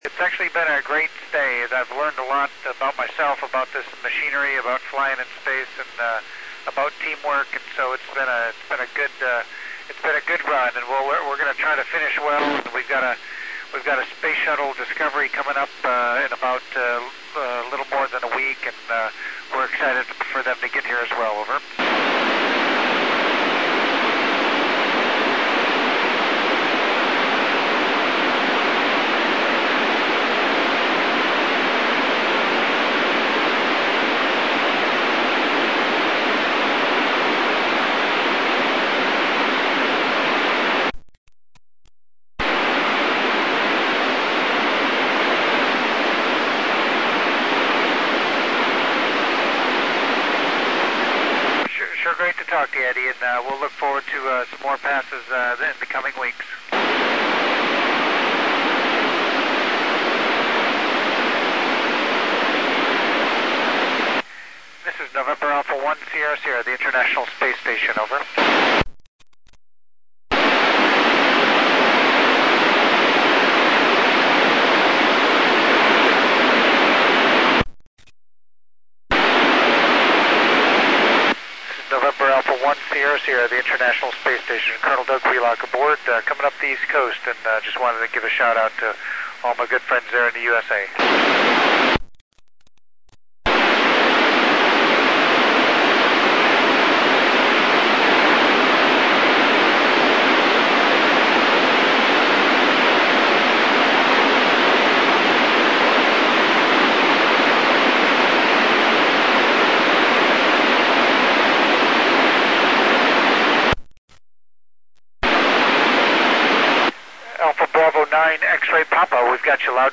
heard alot of ppl making contacts with iss here you go